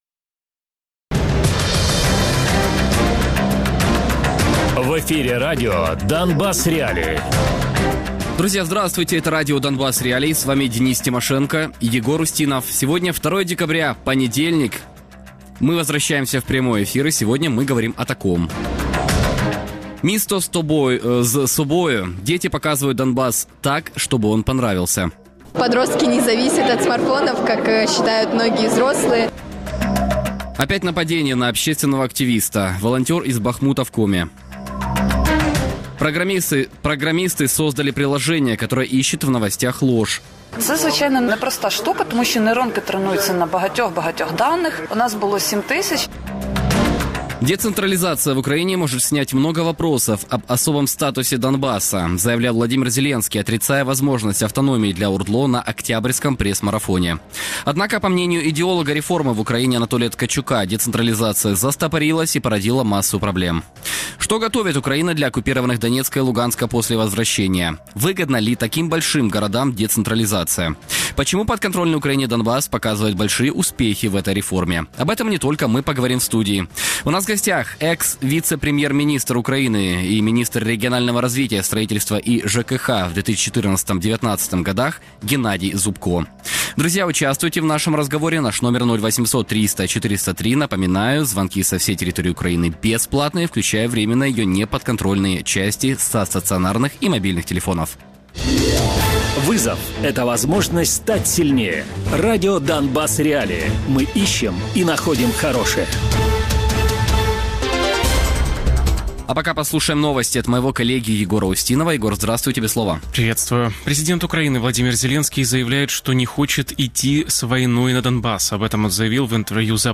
Гості: Геннадій Зубко - екс-віце-прем'єр-міністр України, міністр регіонального розвитку, будівництва та житлово-комунального господарства (2014-2019 рр.) Радіопрограма «Донбас.Реалії» - у будні з 17:00 до 18:00.